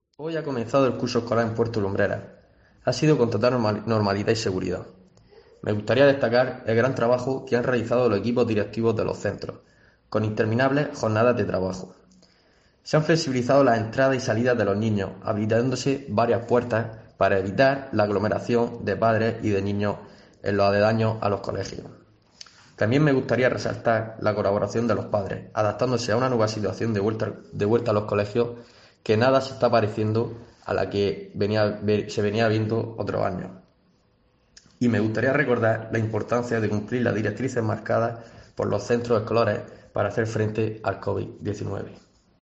José Manuel Sánchez, concejal educación Puerto Lumbrera sobre inicio curso escolar